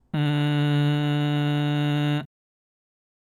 まずは、喉頭は普段の状態でグーの声を使いながら鼻、鼻口、口の順に「え」出していく。
※喉頭は普段の状態でグーで鼻